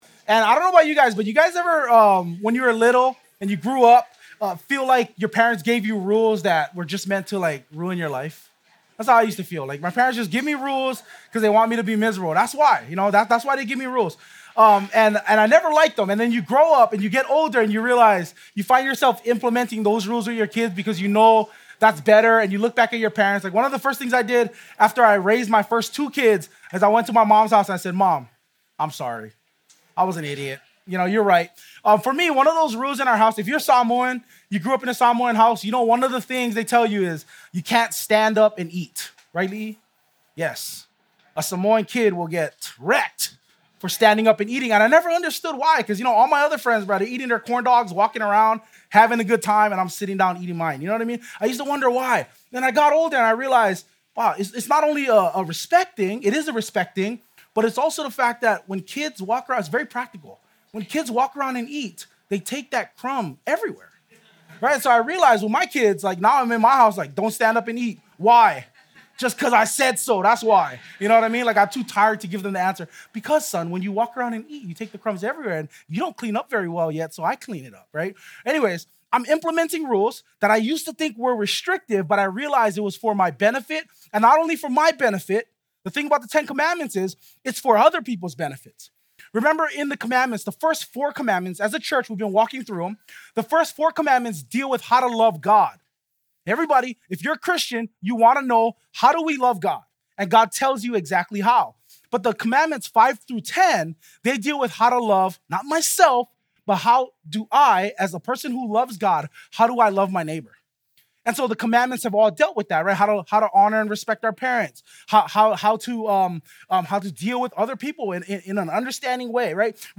2025 Freed to Speak Truth Preacher